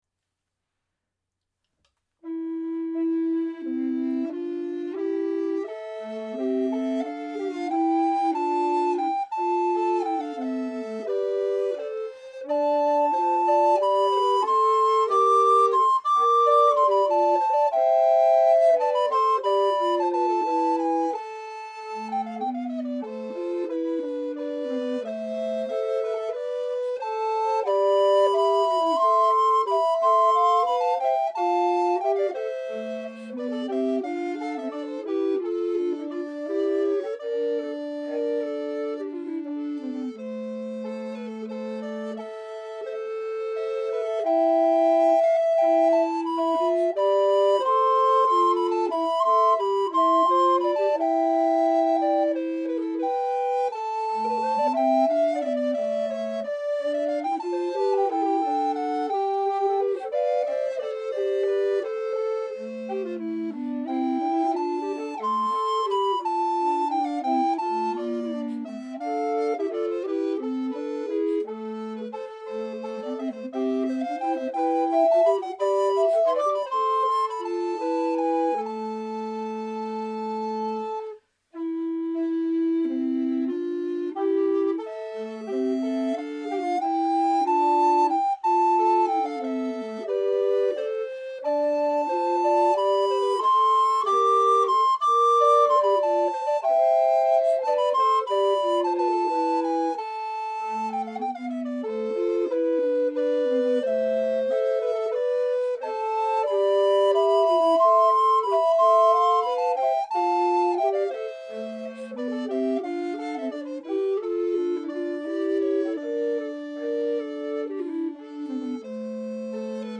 Music from the 15th and 16th centuries
recorders
at the Loring-Greenough House, Jamaica Plain